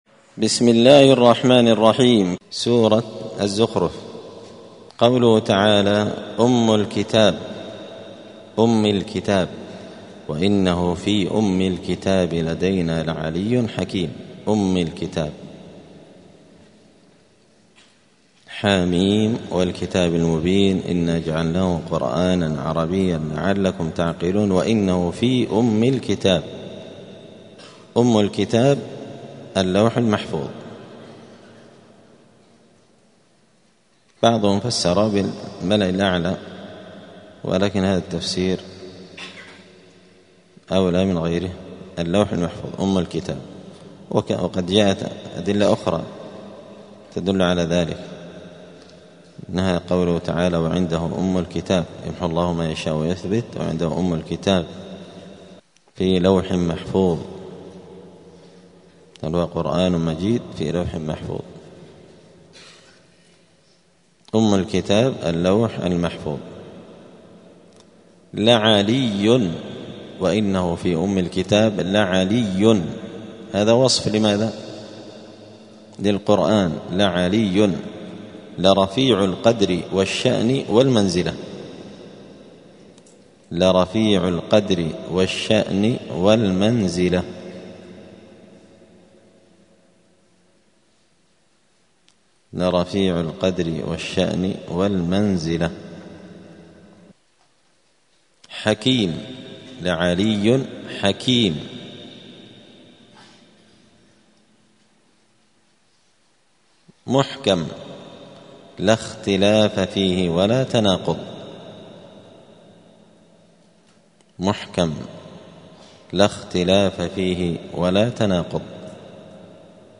زبدة الأقوال في غريب كلام المتعال الدرس الثاني والثلاثون بعد المائتين (232)